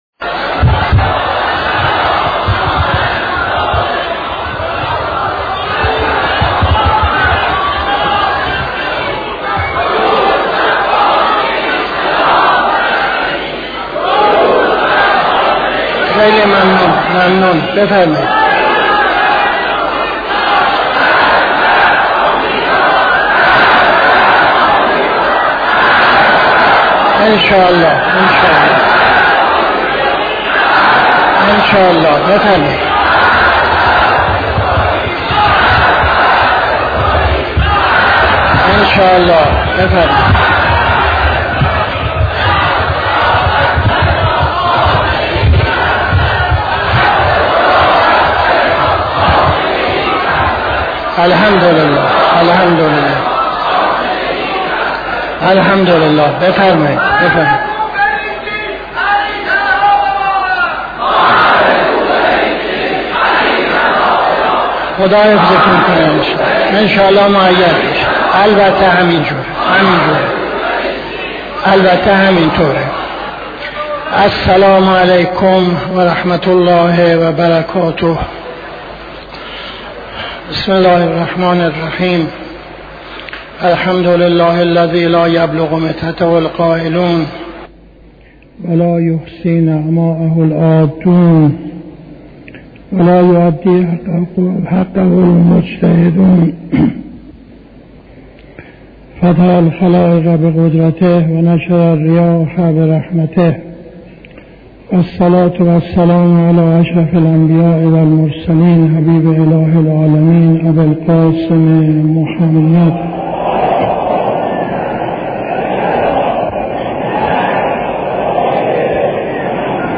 خطبه اول نماز جمعه 16-07-78